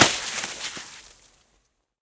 snowhit.ogg